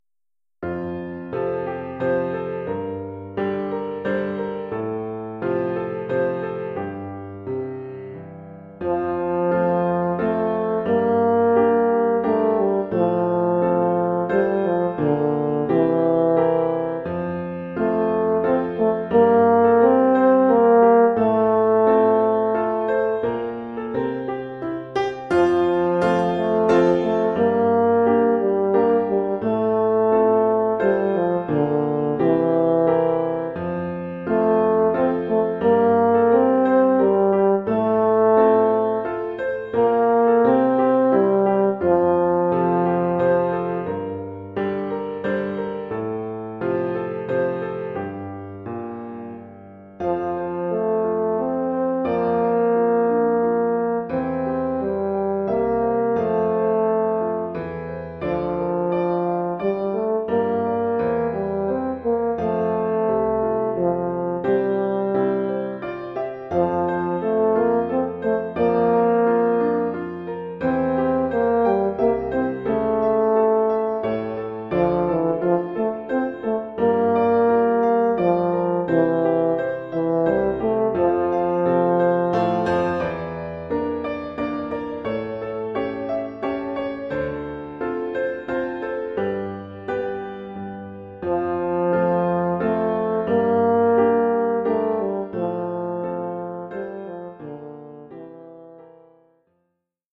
Collection : Saxhorn alto
Oeuvre pour saxhorn alto et piano.